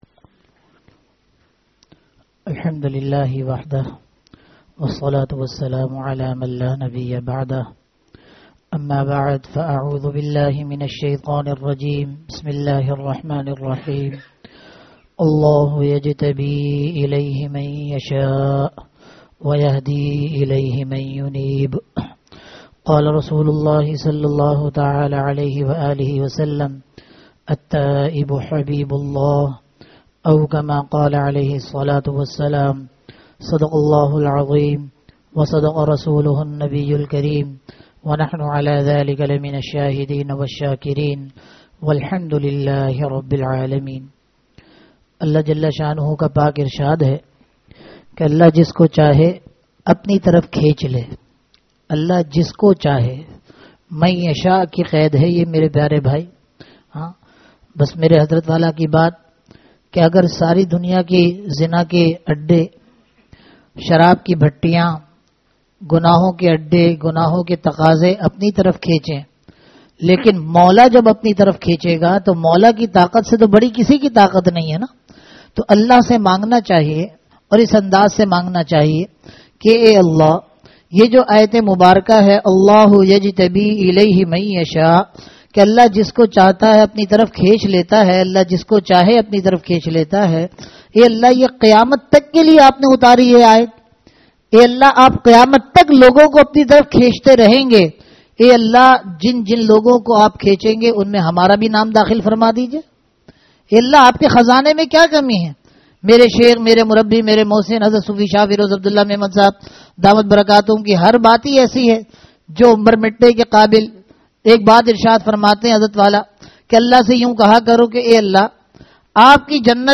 Bayanat
Bayanat Bayanat (Jumma Aur Itwar) Jazb ke waqiat (Jummah) 19th May 2023 Category Bayanat Sub-Category Bayanat (Jumma Aur Itwar) Date 19th May 2023 Size 6.81 MB Tags: Download Source 1 Download Source 2 Share on WhatsApp